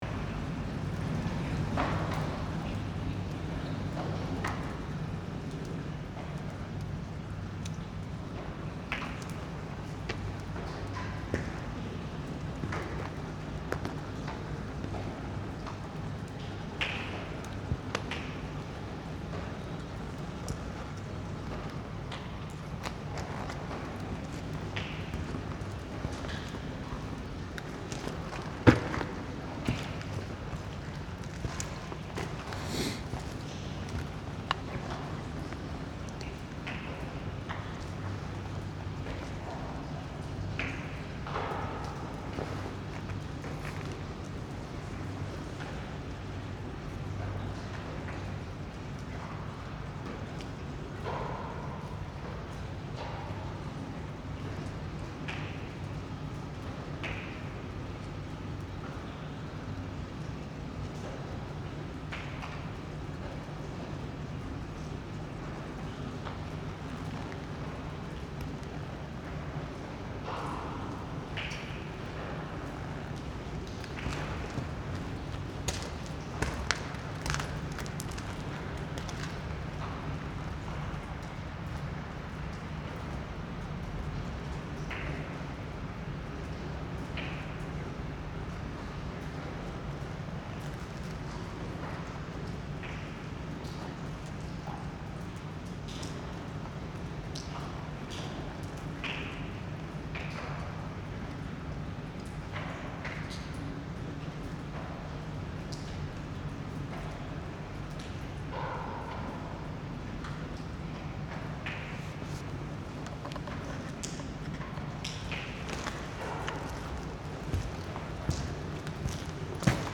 These are sounds mostly from a building site in Copenhagen NV, with sounds of water splashing.
Walking around the building site
walking_around_building_site.mp3